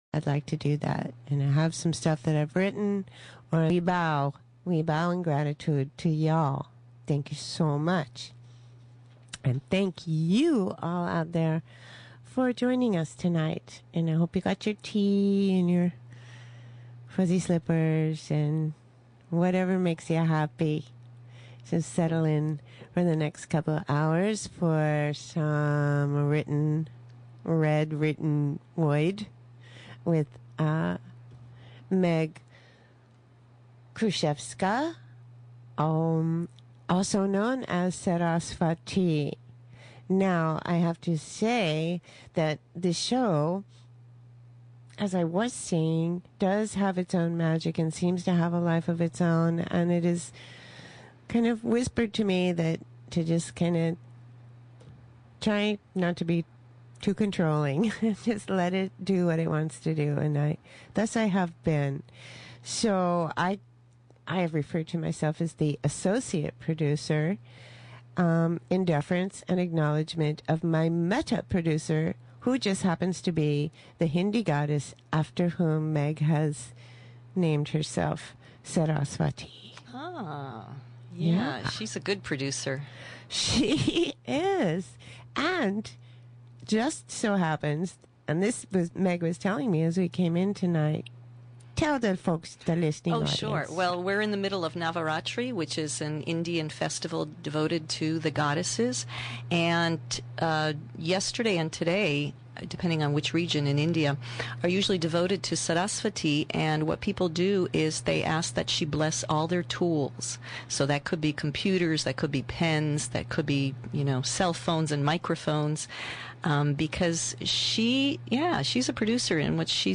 Recorded Interview and Reading (poetry, Desdemona’s Children)
radio-reading.mp3